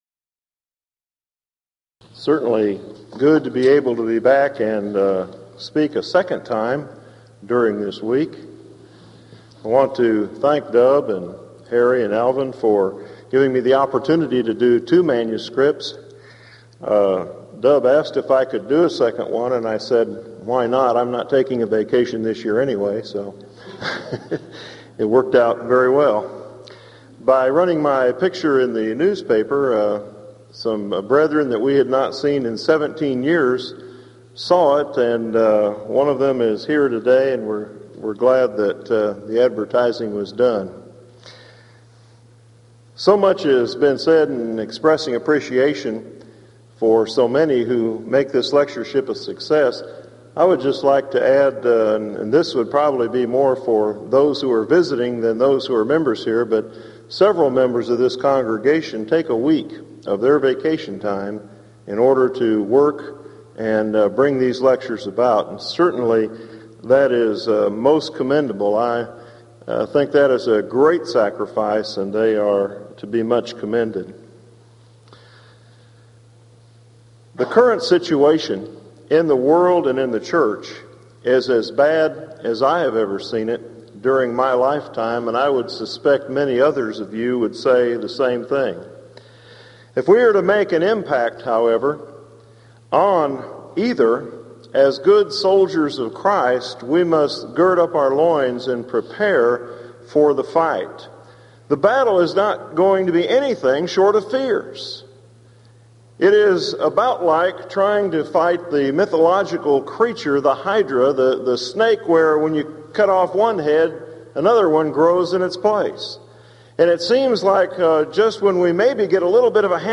Event: 1993 Denton Lectures Theme/Title: Studies In I & II Kings, I & II Chronicles